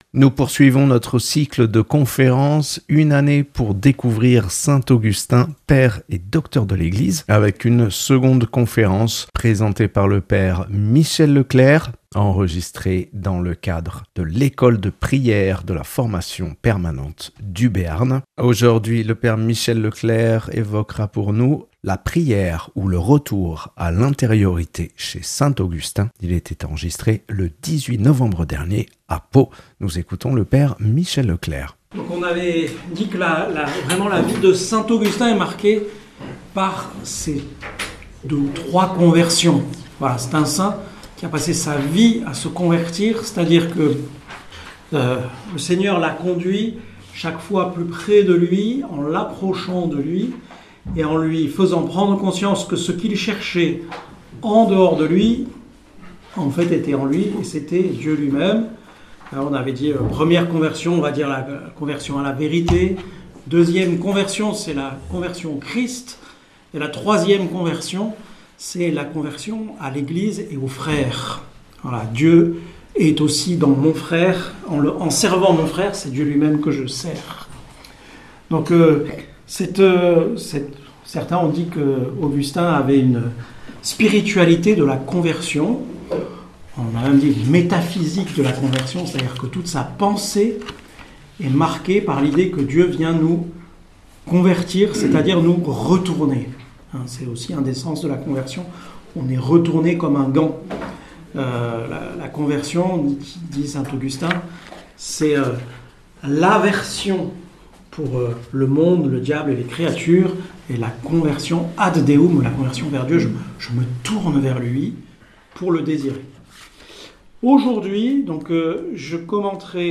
(enregistré à Pau le 18 novembre 2025)
Une conférence